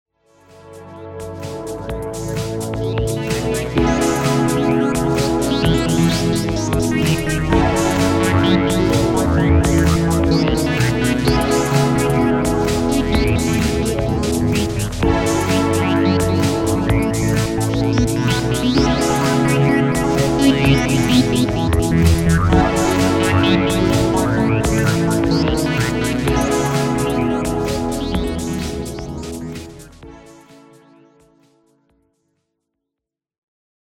Shruthi-1 Pad Demo.mp3